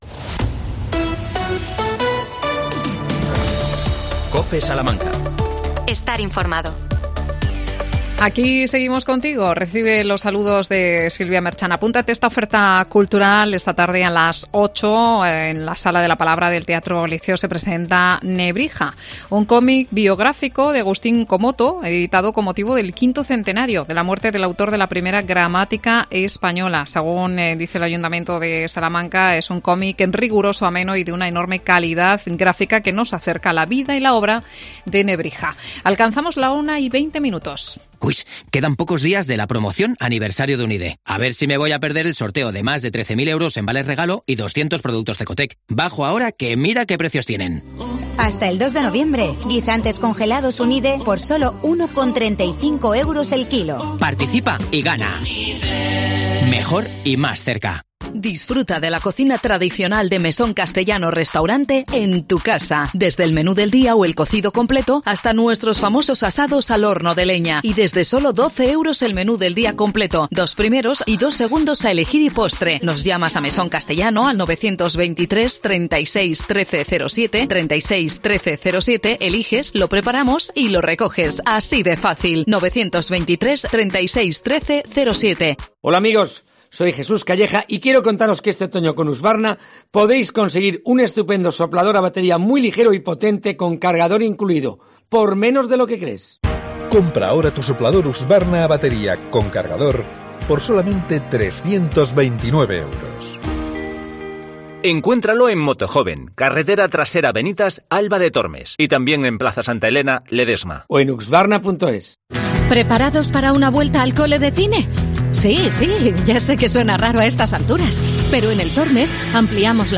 Villares de la Reina Acoge la I Feria de Miniaturas los días 29 y 30 de octubre. Entrevistamos al portavoz del equipo de gobierno Fernando Martínez Vallvey.